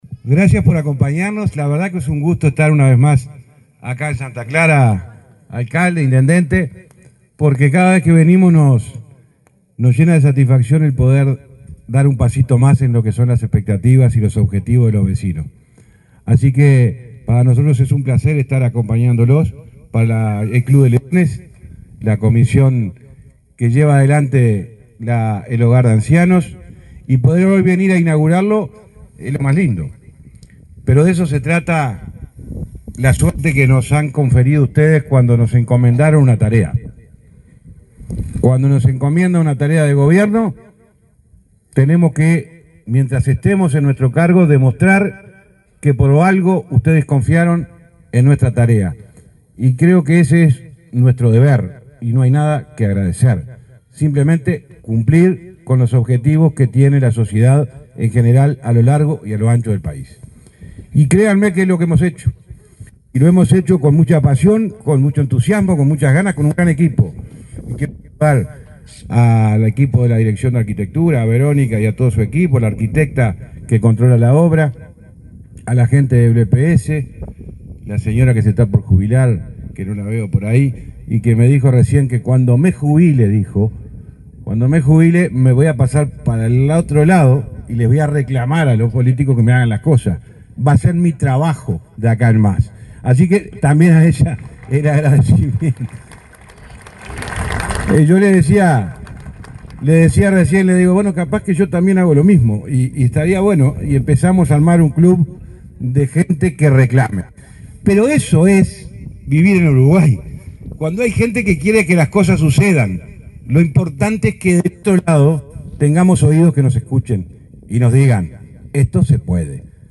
Palabras del ministro de Transporte, José Luis Falero 03/10/2024 Compartir Facebook X Copiar enlace WhatsApp LinkedIn El ministro de Transporte y Obras Públicas, José Luis Falero, se expresó en oratoria durante la inauguración de un hogar de ancianos en Santa Clara de Olimar, departamento de Treinta y Tres.